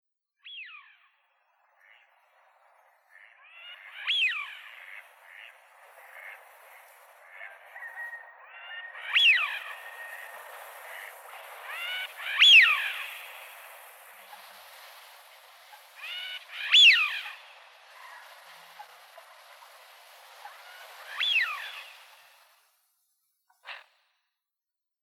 Yleisimpien ”riistasorsien” ääninäytteitä
Haapana